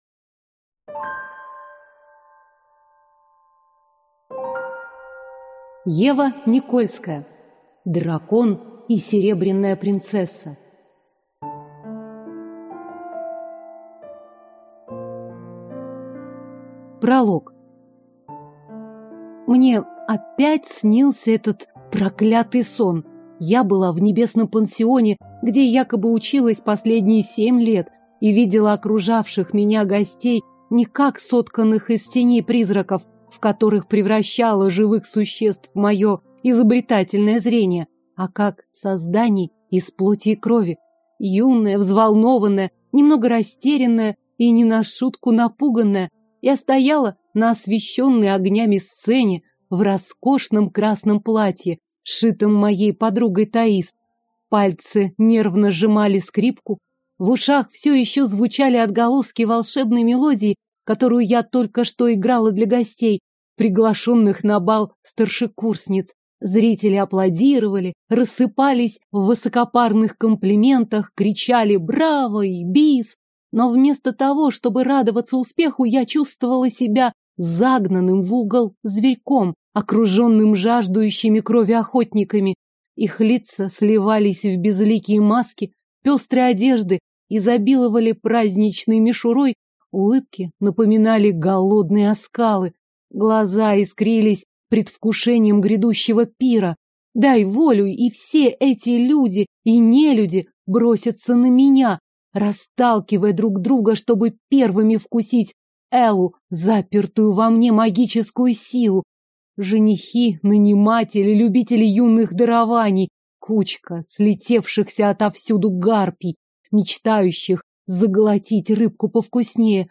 Аудиокнига Дракон и серебряная принцесса | Библиотека аудиокниг